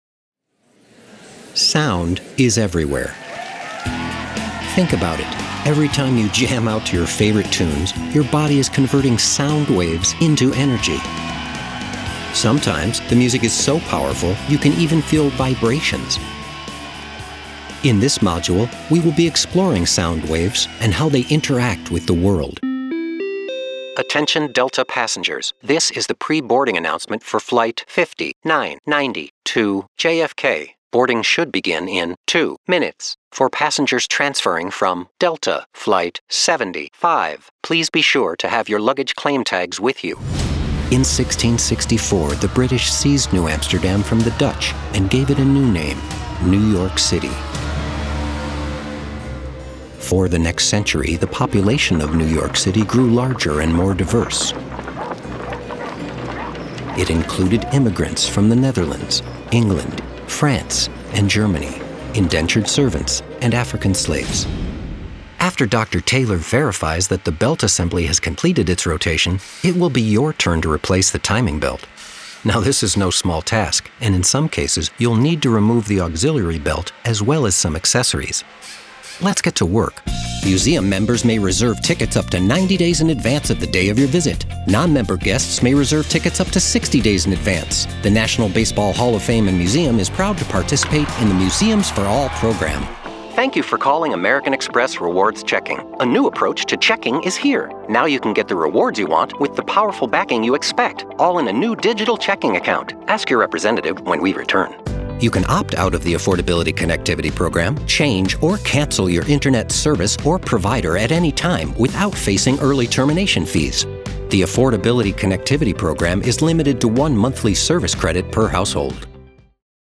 E-Learning, Narration, Documentary